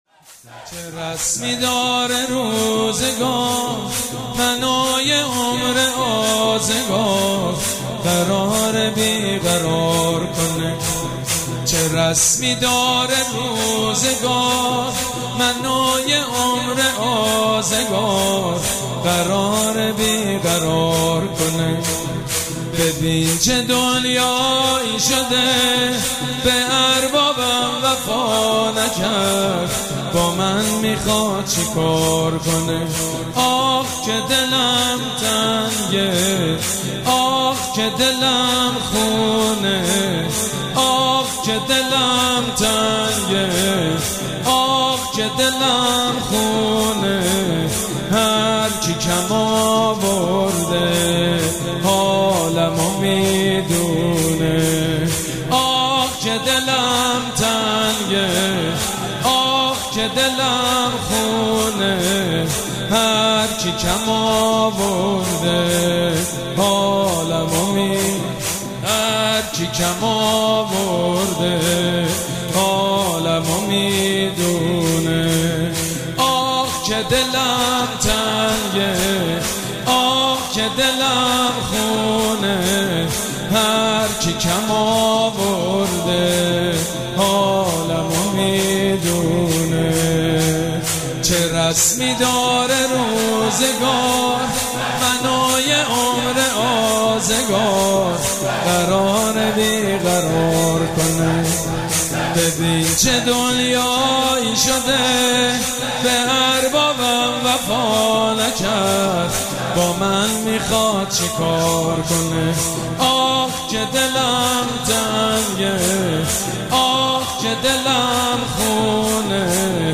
مراسم عزاداری شب هشتم محرم الحرام ۱۴۴۷
شور
مداح
حاج سید مجید بنی فاطمه